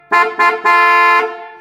8. Водитель волги сигналит
gaz24-signal-2.mp3